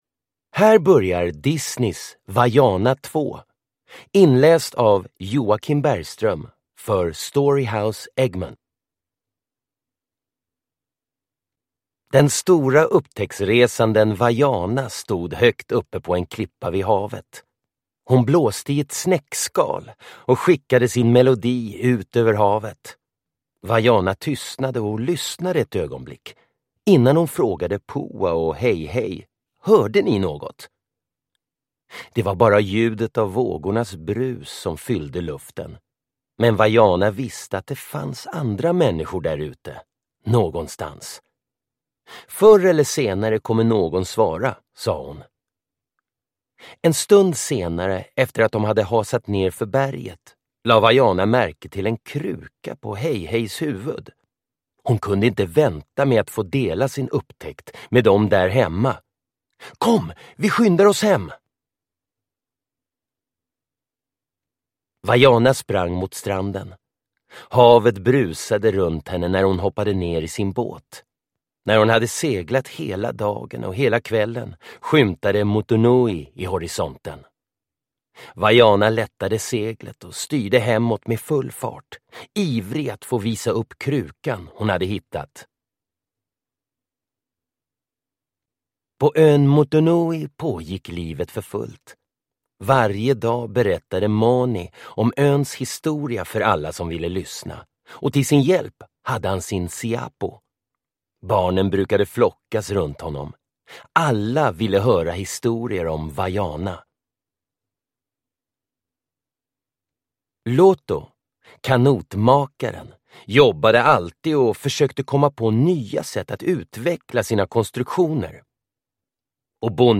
Vaiana 2 – Ljudbok